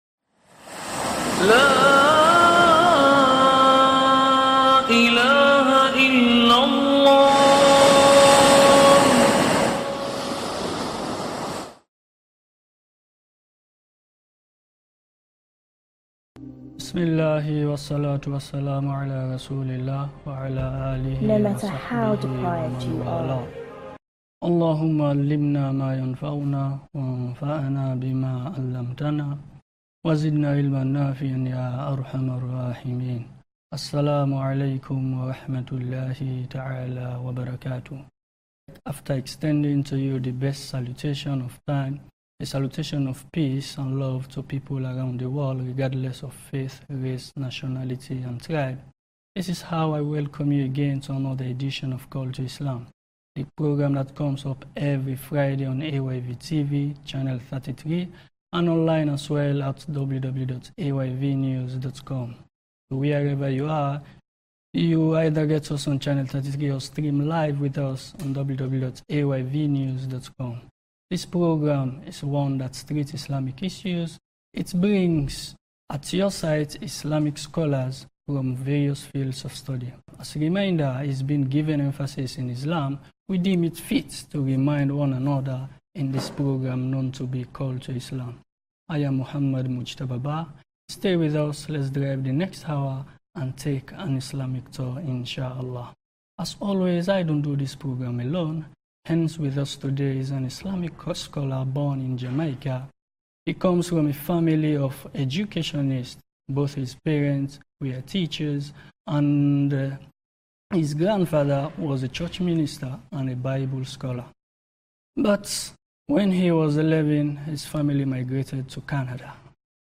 Call to Islam Interview.mp3